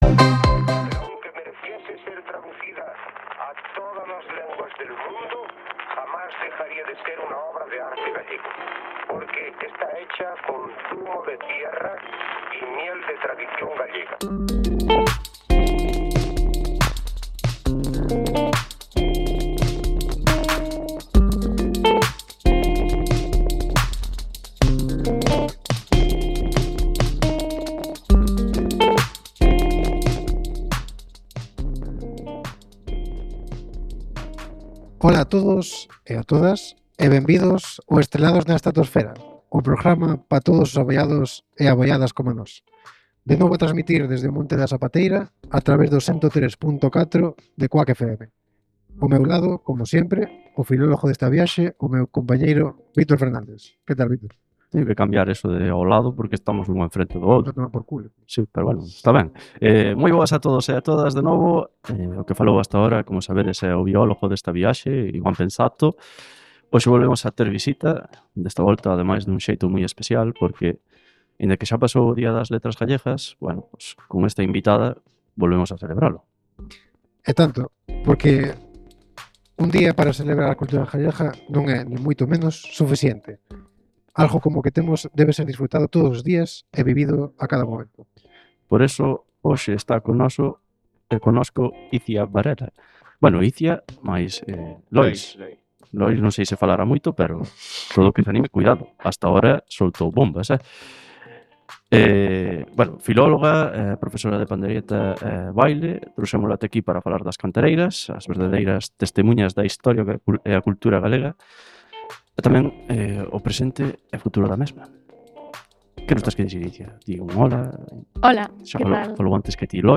Recordade, os xoves a partires das 18:00 en CUAC FM no dial 103.4. PD: pedimos desculpas pola calidade do audio, un micro no estudio de CUAC xenerou un crepitar no son que tentamos de eliminar con programas de eliminación de ruido para non perder o programa gravado. Por este motivo, o son non quedou na calidade que quixeramos.